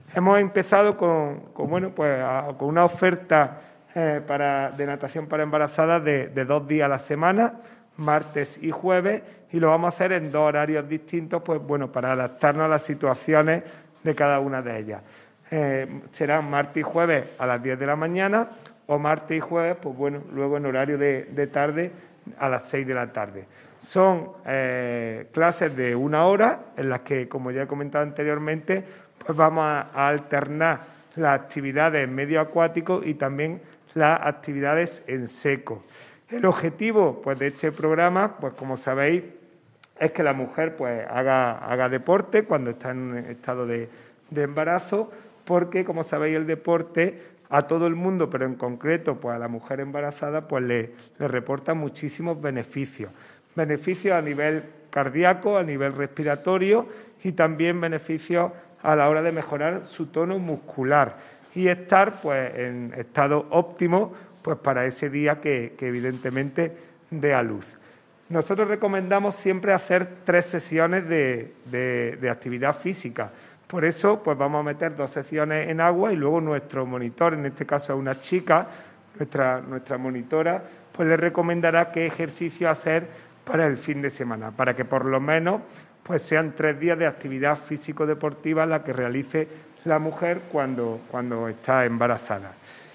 Mejorar el estado físico y la preparación de cara al parto de las mujeres embarazadas. Ese es el objetivo de las nuevas clases específicas para este colectivo que el Área de Deportes comenzará a desarrollar en febrero tal y como ha confirmado hoy en rueda de prensa el teniente de alcalde Juan Rosas.
Cortes de voz